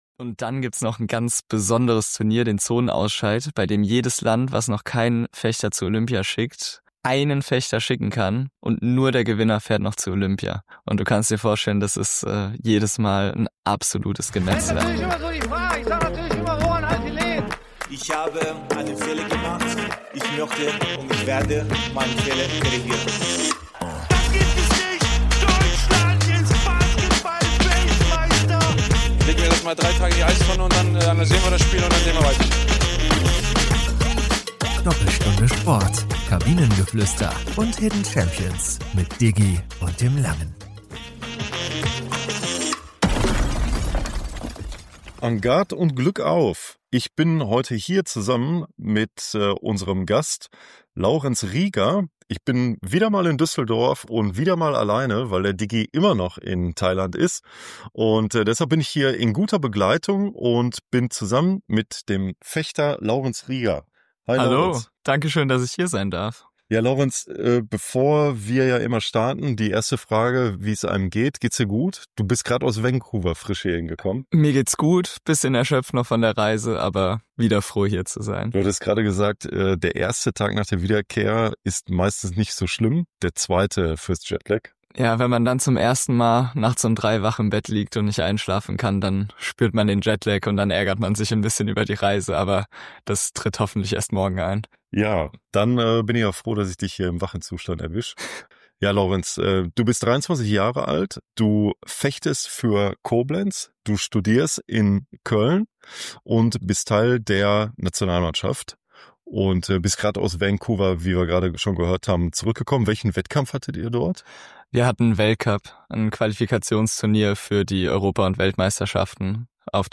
In dieser spannenden Episode sprechen wir mit dem erfolgreichen Fechter